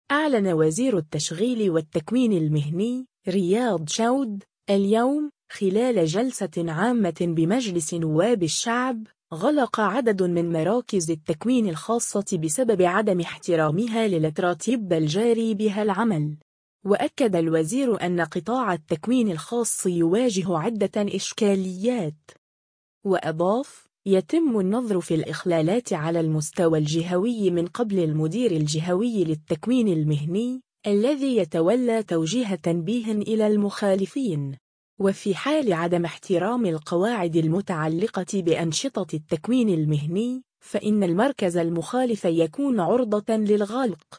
أعلن وزير التشغيل والتكوين المهني، رياض شود، اليوم، خلال جلسة عامة بمجلس نواب الشعب، غلق عدد من مراكز التكوين الخاصة بسبب عدم احترامها للتراتيب الجاري بها العمل. وأكد الوزير أن قطاع التكوين الخاص يواجه عدة إشكاليات.